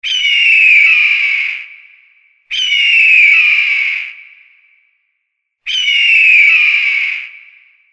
Screaming Hawk Sound Effect ringtone free download
Animals sounds